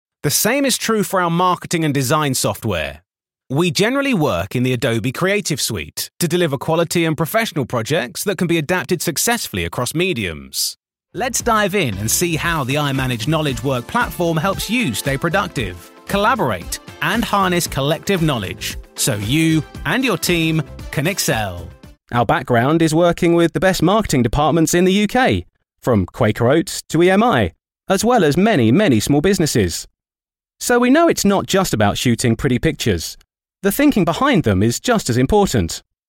Inglés (Británico)
Comercial, Natural, Amable, Cálida, Empresarial
Corporativo